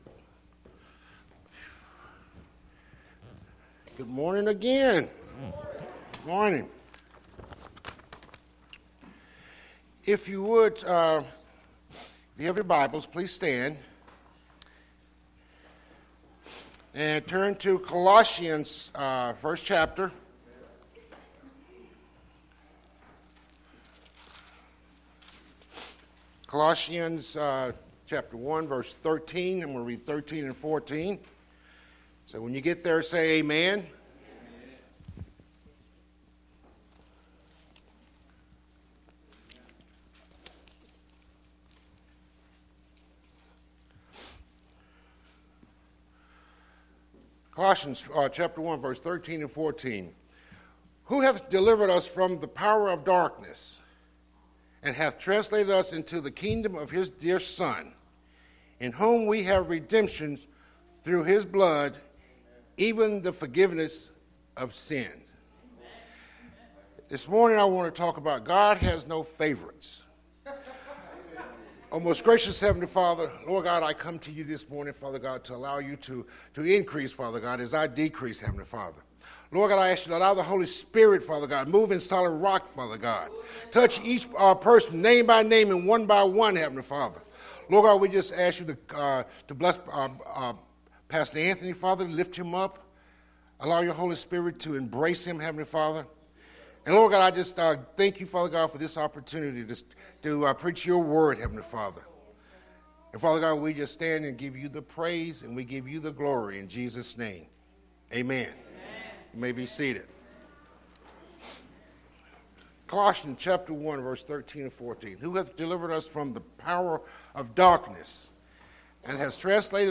Solid Rock Baptist Church Sermons